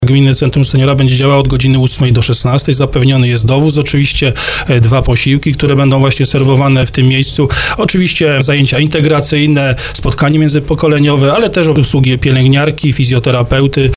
– To miejsce powstało z myślą o naszych seniorach. Chcemy, by było przestrzenią bezpieczną, aktywną i pełną życia – podkreślał w programie Słowo za Słowo burmistrz Miasta i Gminy Zakliczyn, Dawid Chrobak.